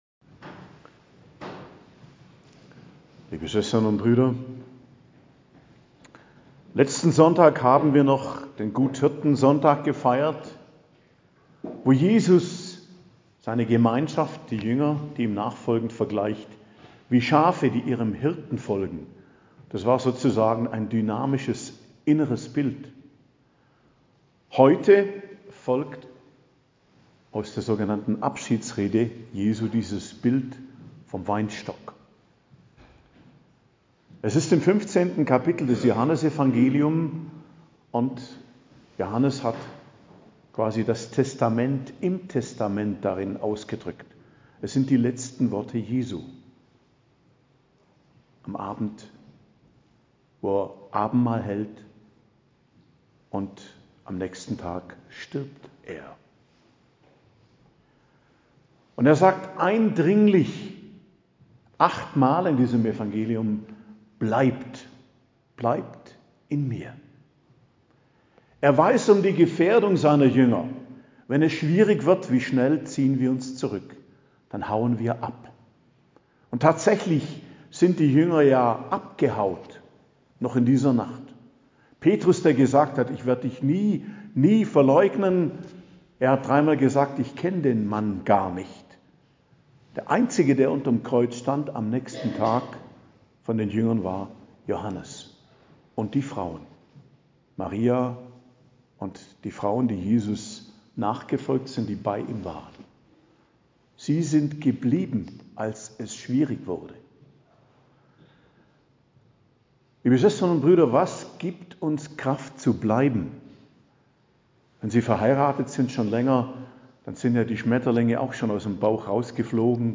Predigt zum 5. Sonntag der Osterzeit, 28.04.2024 ~ Geistliches Zentrum Kloster Heiligkreuztal Podcast